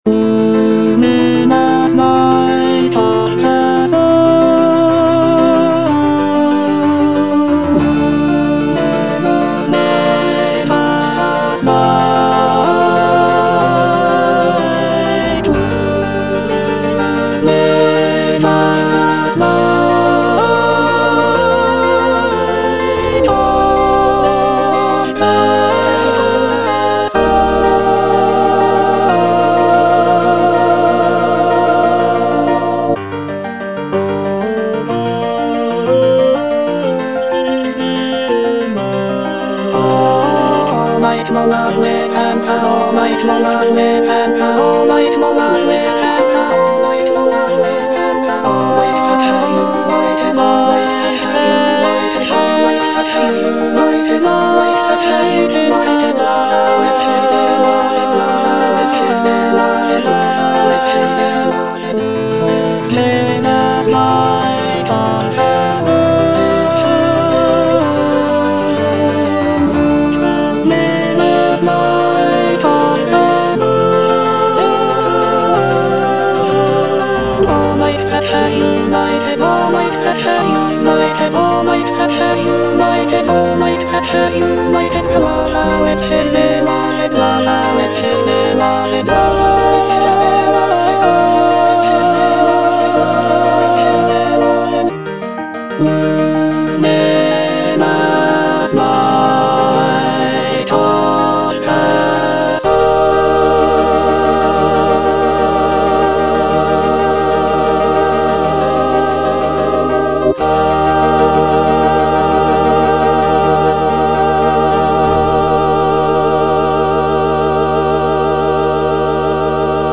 alto II) (Emphasised voice and other voices) Ads stop